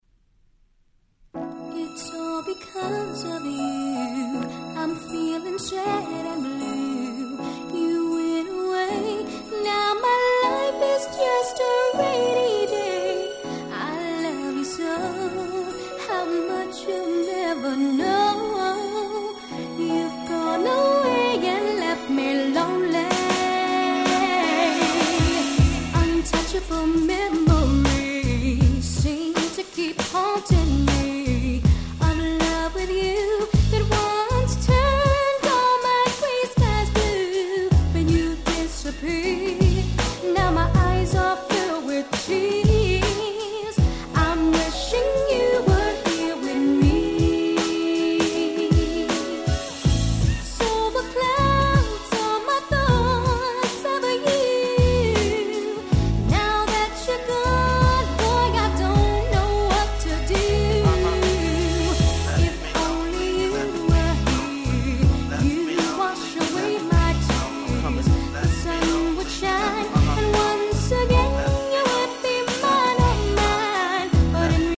※試聴ファイルは別の盤から録音してあります。
バラード Slow Jam スロウジャム